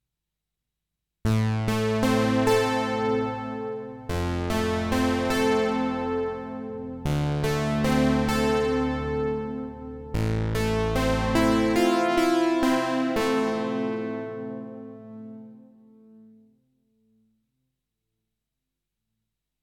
Моя проба на быструю руку на REV2 ) Голый патч Тут хоруса встроеного еще накинул Вложения UDO-REV2.mp3 UDO-REV2.mp3 461,6 KB · Просмотры: 3.448 UDO-REV2-Chrorus.mp3 UDO-REV2-Chrorus.mp3 461,6 KB · Просмотры: 3.444